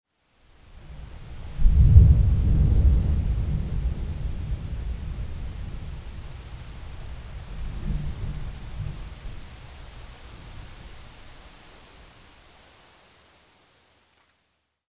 rain_thunder01.mp3